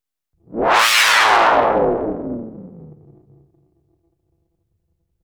A4 ROAR (hoover) attempt.
Noise with lofi S&H
Saw with PWM
Filter 1 : Low pass, env depth 63
Filter 2 : Hi pass, env depth 30